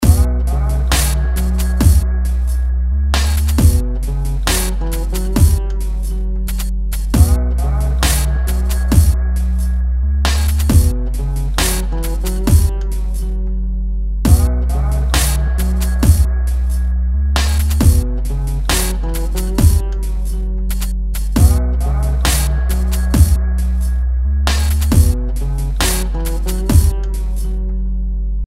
Problem is I can't get a decent wobble to layer on the sub.